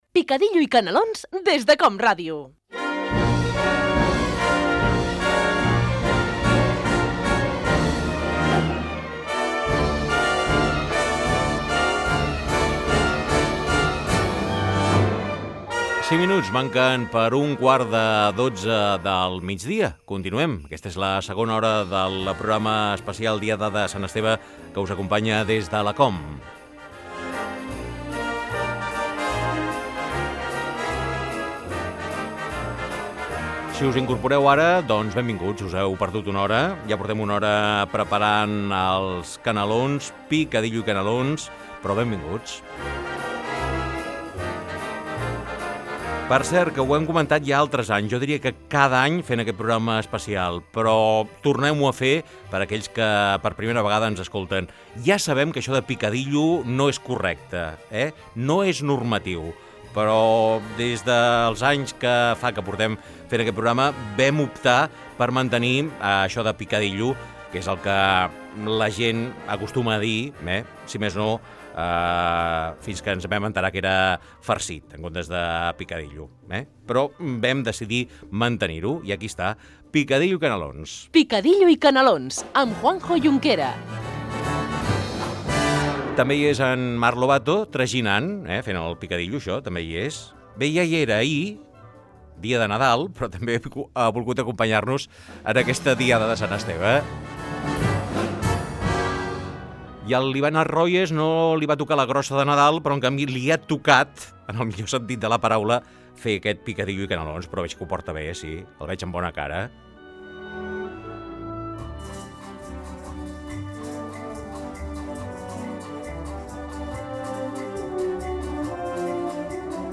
Indicatiu del programa, hora, la paraula "picadillo", indicatiu, equip, sumari de la segona hora del programa. Fragment d'una entrevista al cantant Cris Juanico.
Entreteniment